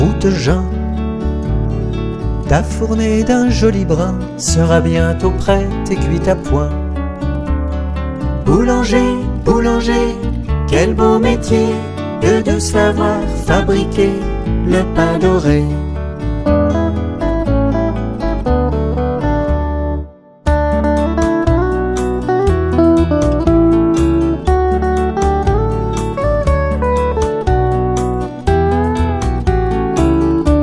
Musicien. Ens. voc. & instr.